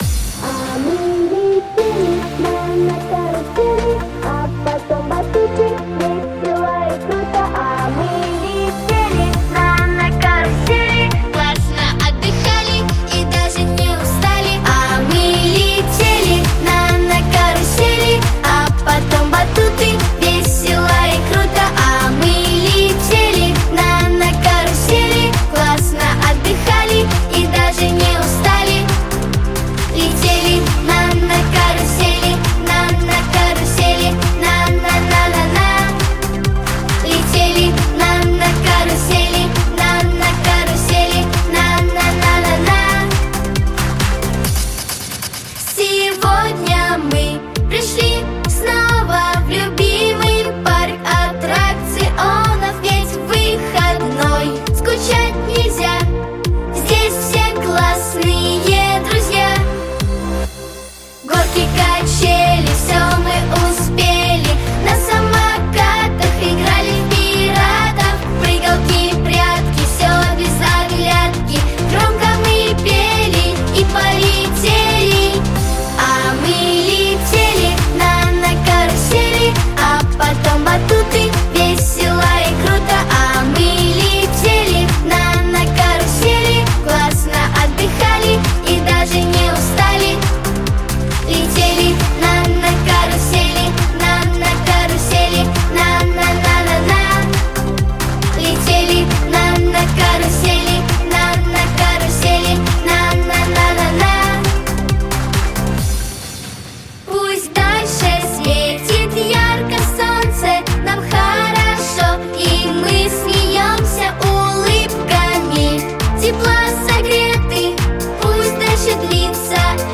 • Категория: Детские песни
🎶 Детские песни / Песни про Лето 🌻